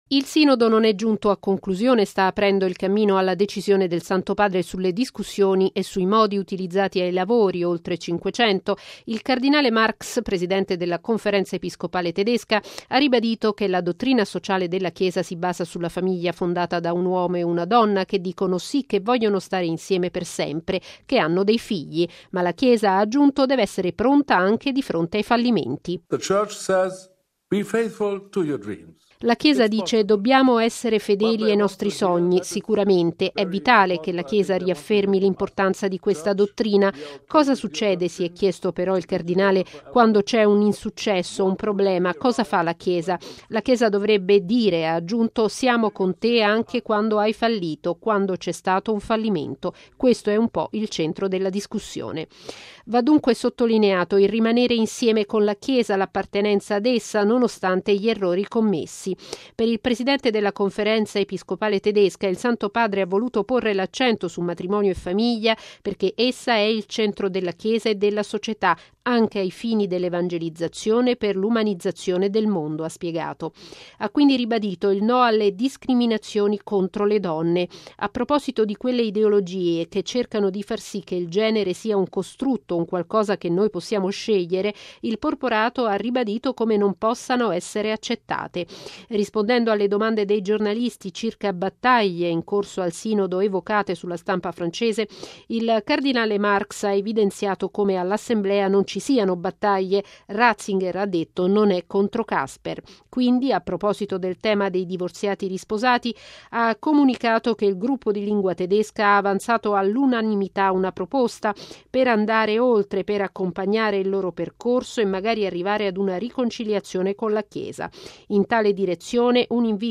Anche se c’è un fallimento nel matrimonio, la Chiesa rimane insieme a chi ha fallito. Il cardinale Reinhard Marx, presidente della Conferenza episcopale tedesca, ha così parlato dei lavori del Sinodo dei vescovi sulla famiglia, durante il briefing nella Sala Stampa della Santa Sede.